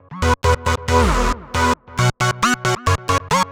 Index of /musicradar/future-rave-samples/136bpm